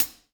HI-HAT - 01.wav